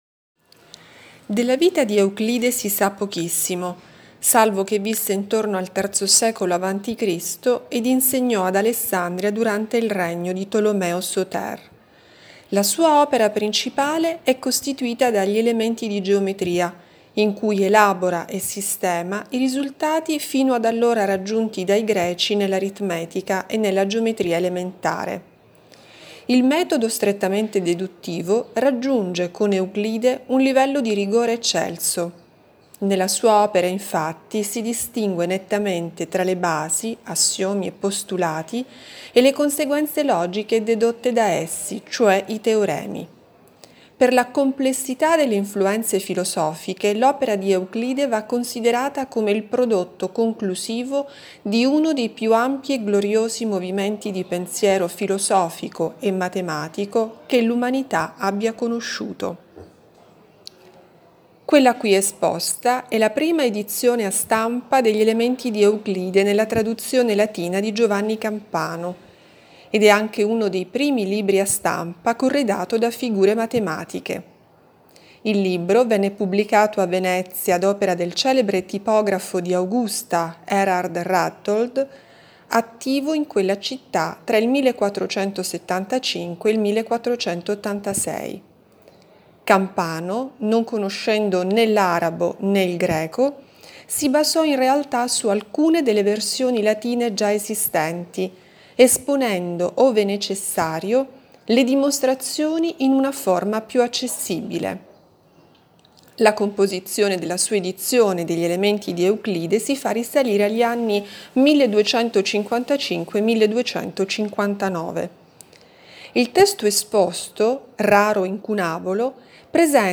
Audioguida Volumi Esposti nel 2022